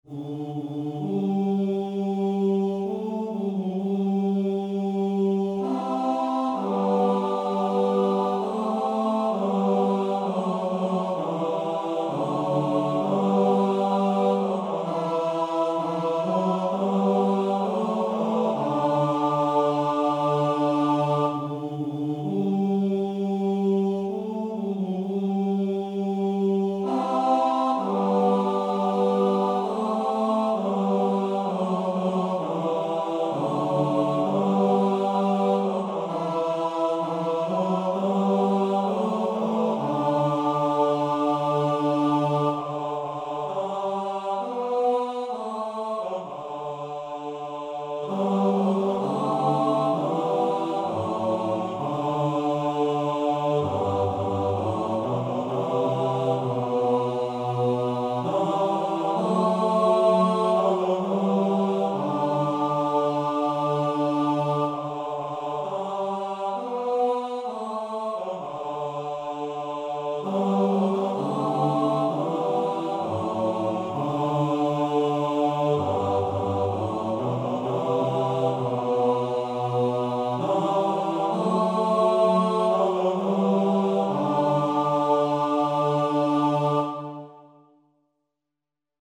– Комп'ютерне відтворення нот: mp3.